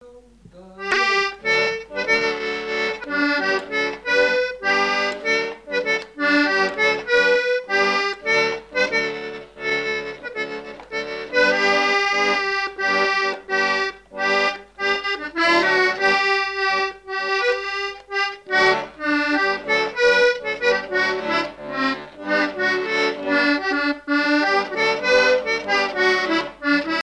Falleron
danse : ronde : grand'danse
Pièce musicale inédite